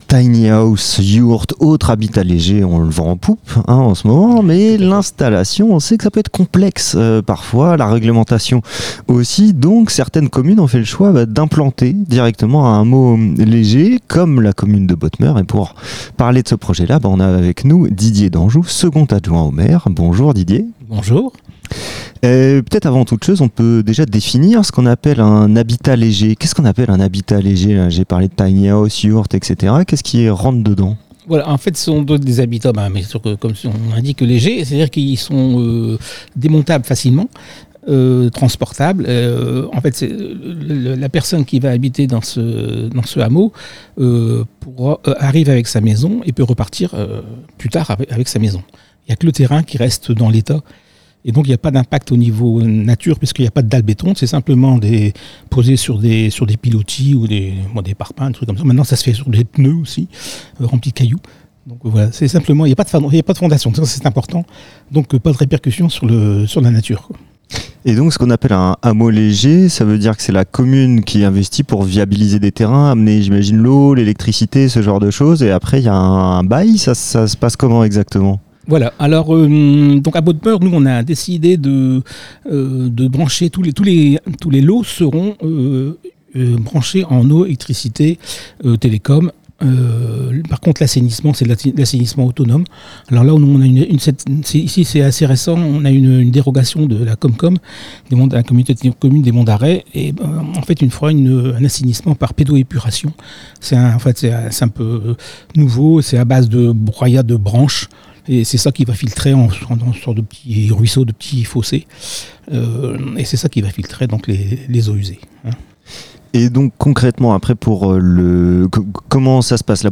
Interview de l'adjoint au maire de Botmeur